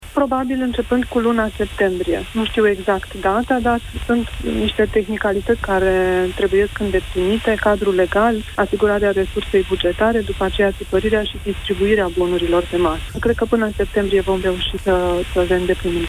Bonurile de masă pentru cei care se vaccinează cu serul împotriva covid 19 ar putea fi eliberate din luna septembrie, a declarat la Europa FM, în emisiunea Deșteptarea, ministrul Sănătății.